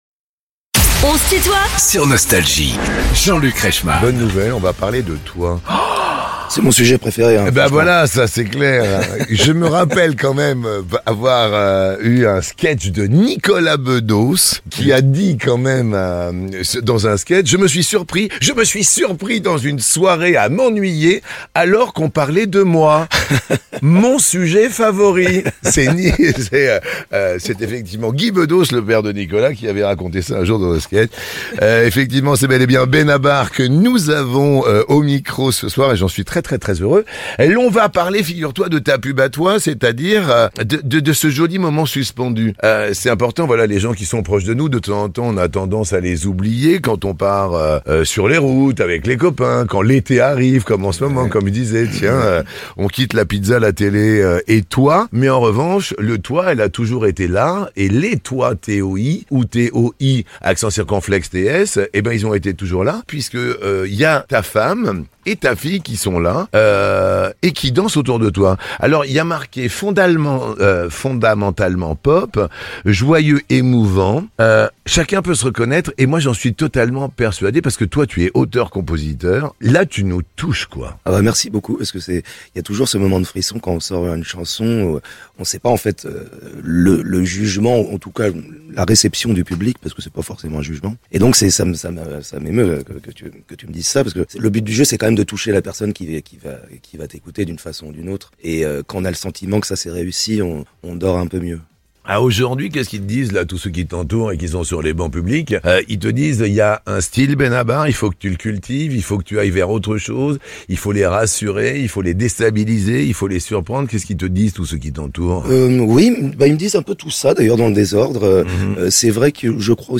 Les interviews
Benabar estl'invité de "On se tutoie ?..." avec Jean-Luc Reichmann